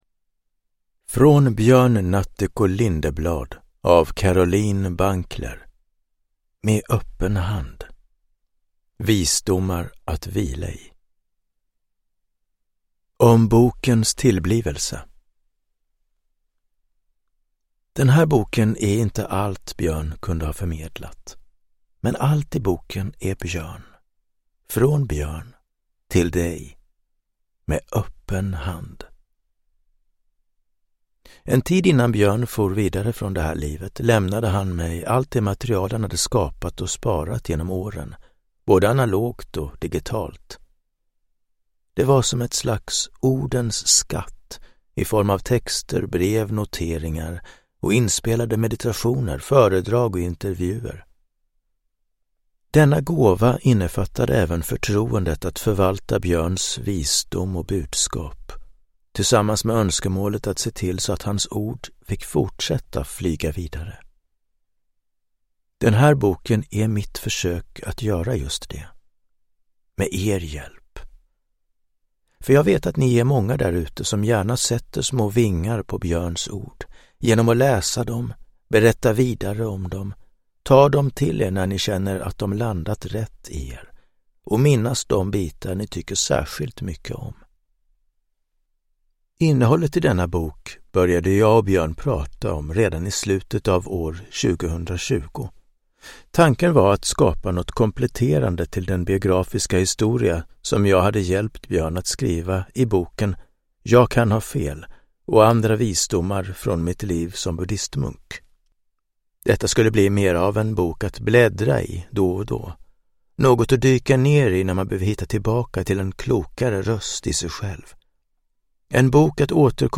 Från Björn Natthiko Lindeblad. Med öppen hand – Ljudbok